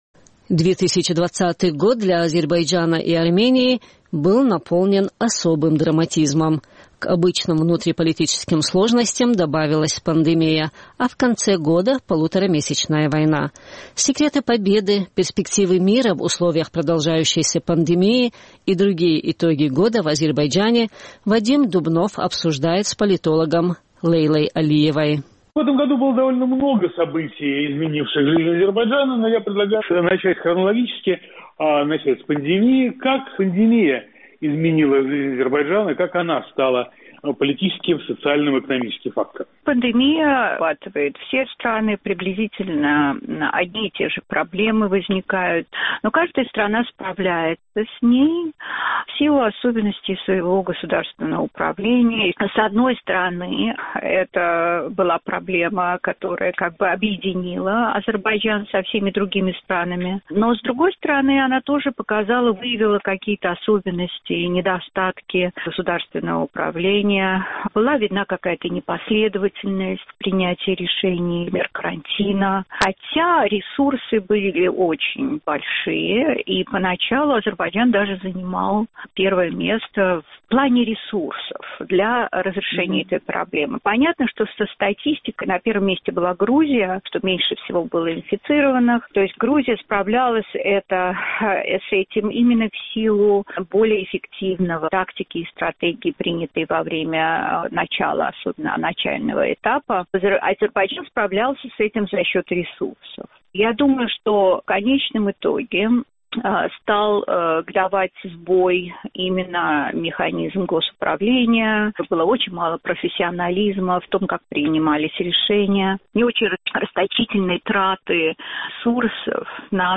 Коронавирус и война – главные события года в Азербайджане, которые затмили все остальное, и радикально изменили жизнь в стране. О политических итогах года военных побед и продолжающейся пандемии мы беседуем с азербайджанским политологом